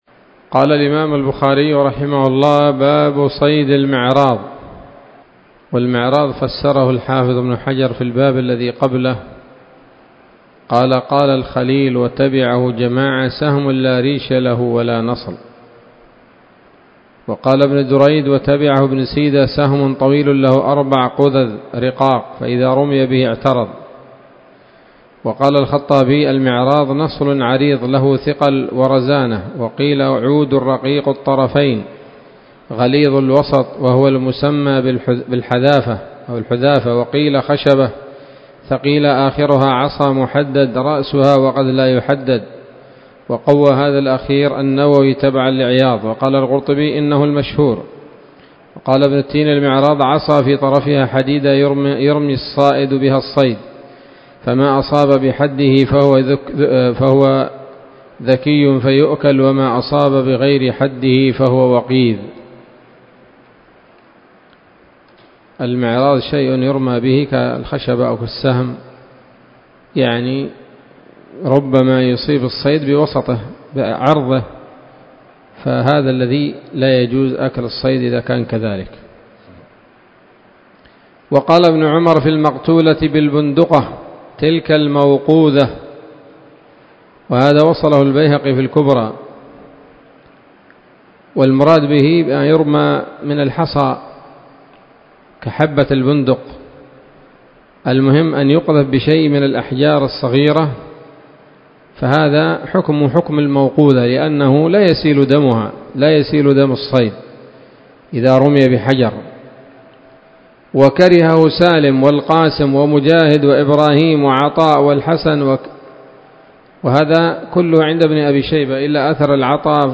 الدرس الثاني من كتاب الذبائح والصيد من صحيح الإمام البخاري